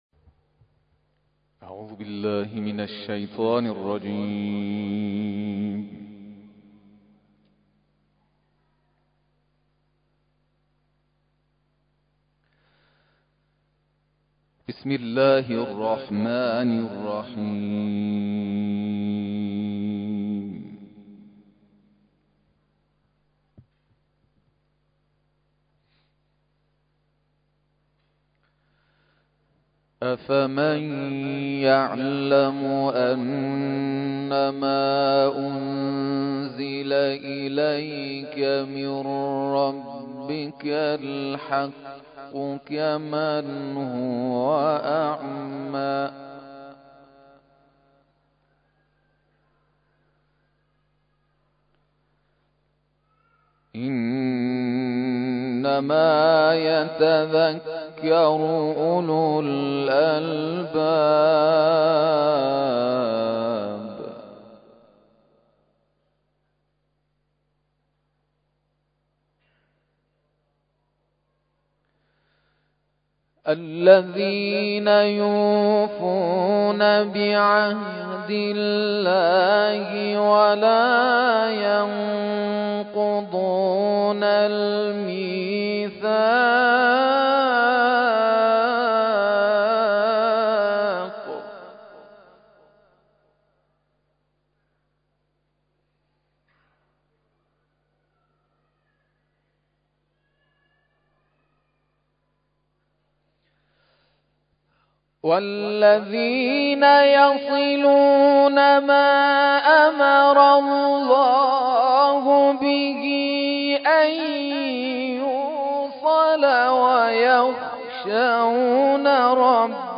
تلاوت ظهر - سوره اسراء آیات (۱ تا ۹)